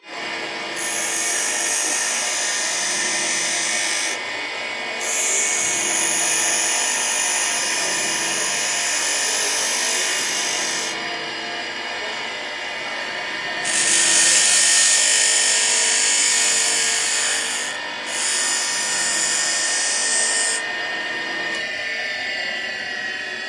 实验性合成器声音 " 金属klmk b2 1
描述：一种非常刺耳但有节奏的金属合成器声音。
标签： 电子 金属加工 科幻 合成器
声道立体声